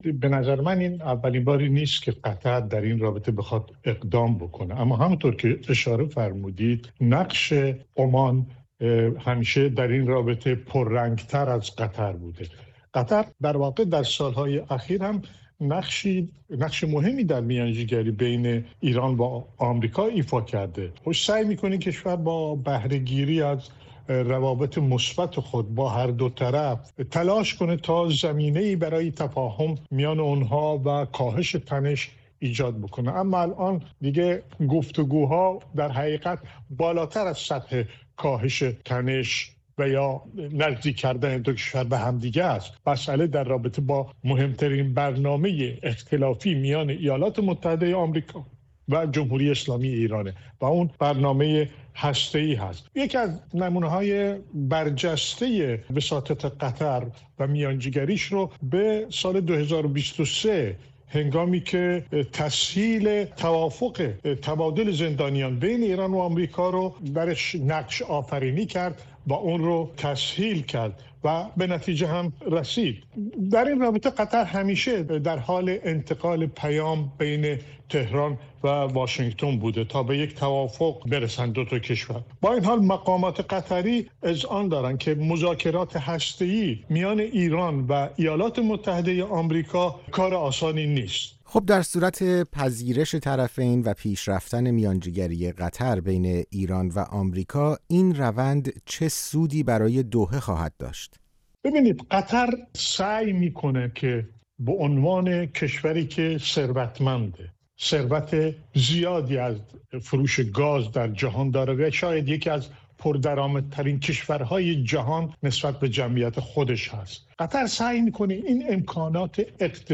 دلایل پیشنهاد قطر برای میانجی‌گری بین ایران و آمریکا؛ گفت‌وگو با یک کارشناس